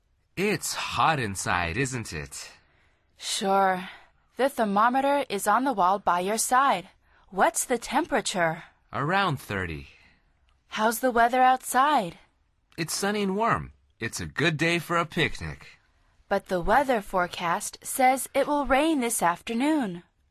Al final repite el diálogo en voz alta tratando de imitar la entonación de los locutores.